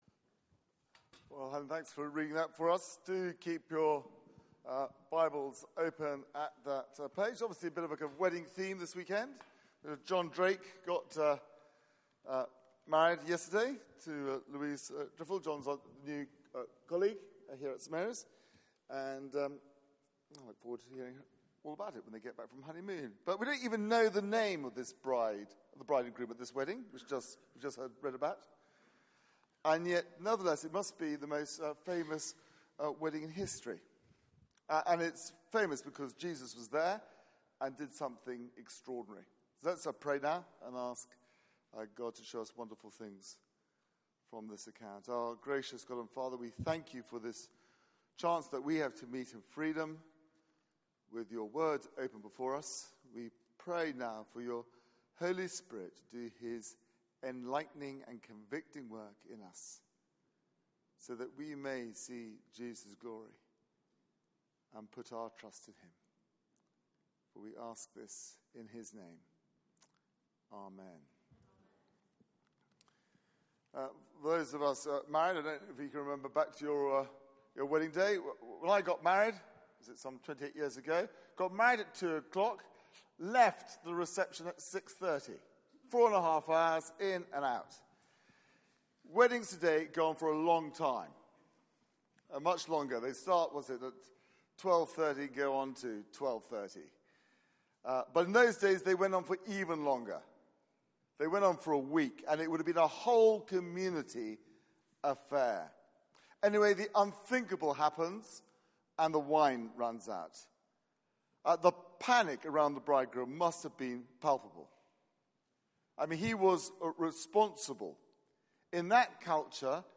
Media for 4pm Service on Sun 12th Jun 2016 16:00 Speaker